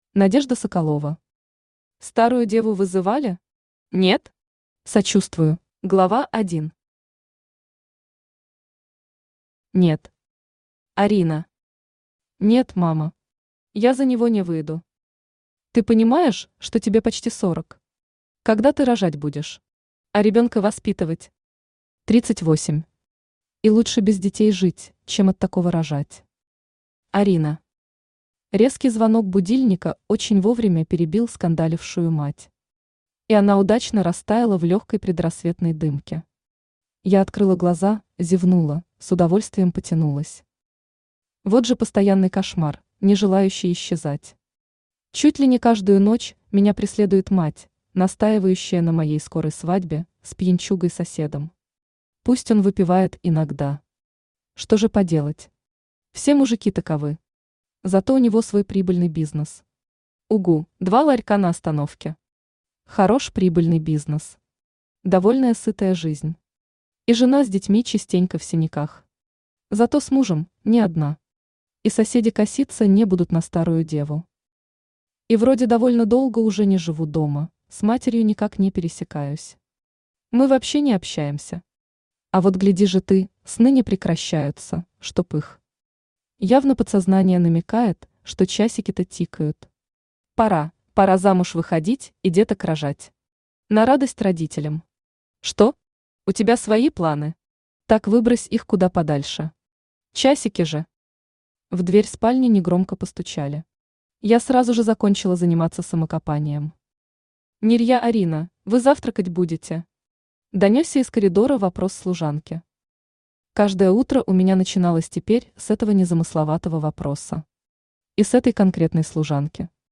Аудиокнига Старую деву вызывали? Нет? Сочувствую